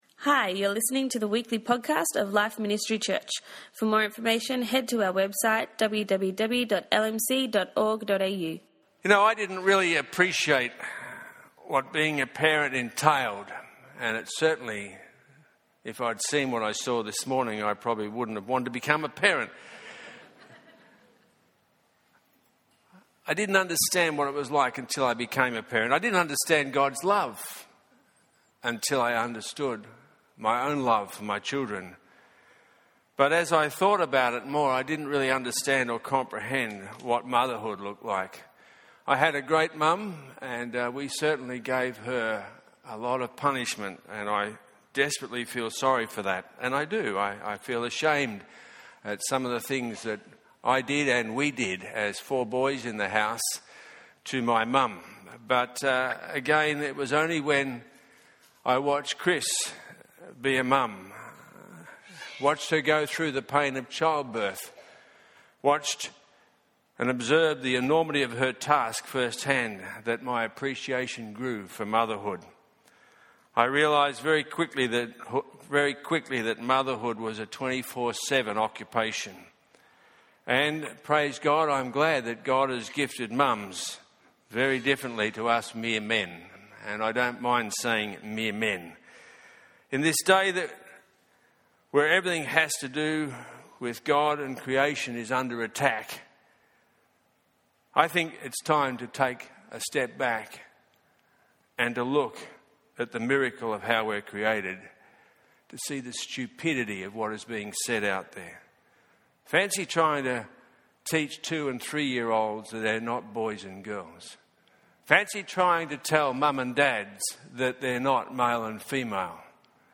At our Mother's Day service of 2016